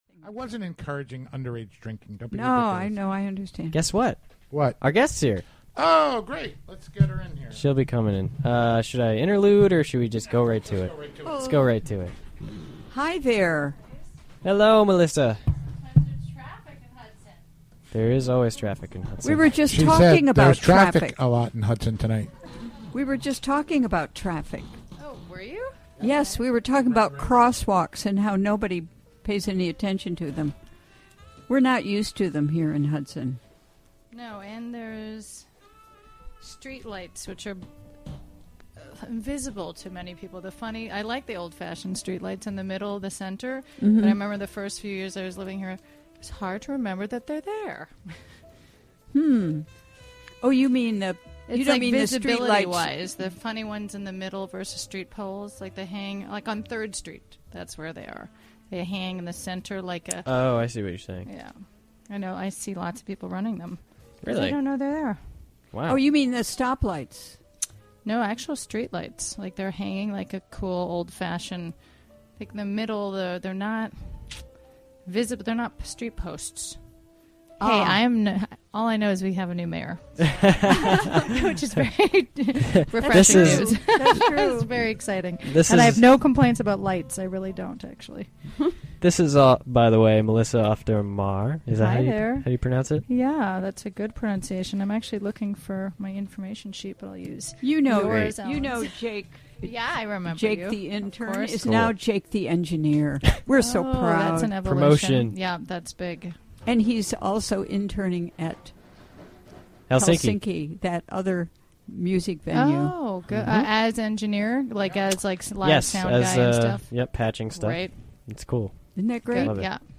Recorded during the WGXC Afternoon Show, Thu., Nov. 19, 2015.